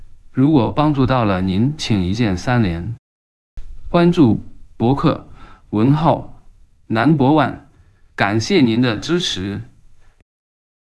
本教程介绍如何使用 Coqui TTSXTTS v2 模型 实现中文语音克隆，支持直接传入 .wav 文件，还原你的音色与语调，生成带有个性化音色的语音合成结果。
克隆过滤版output.wav